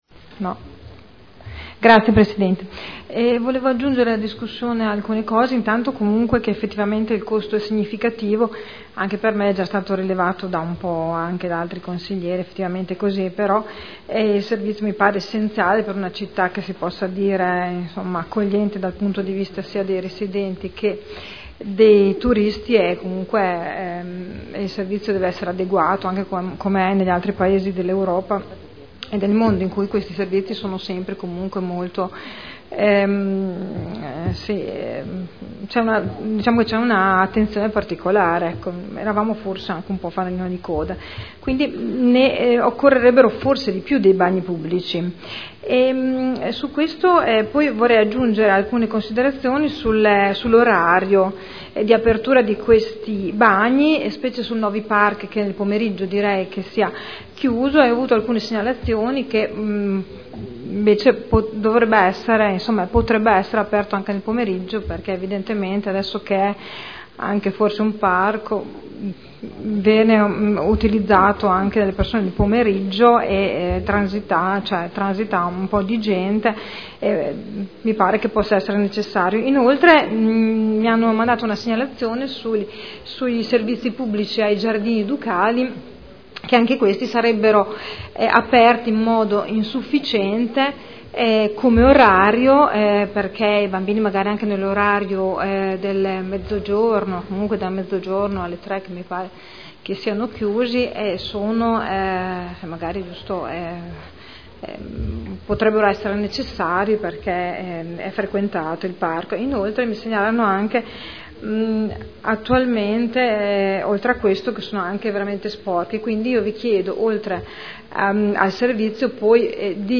Seduta del 12/11/2012 Dibattito su Delibera.